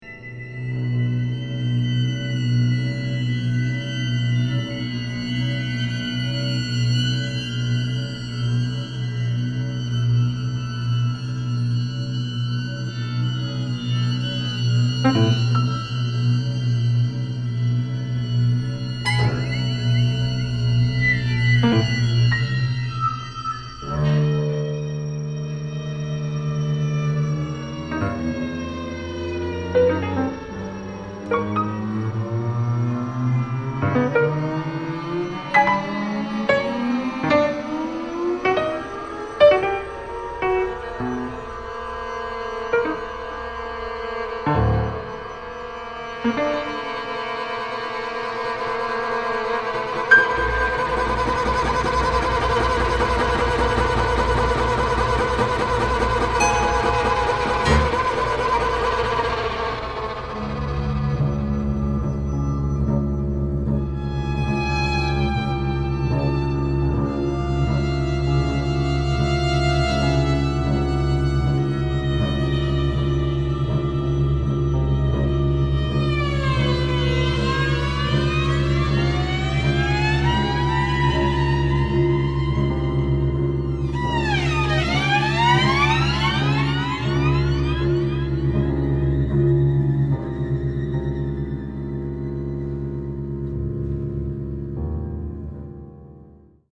for vln, cb, pno, electronics